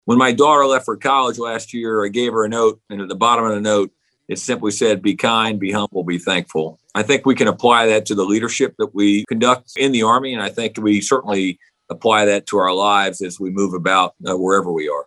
Former and current commanders of Fort Riley joined a virtual panel to discuss leadership both in and out of the military.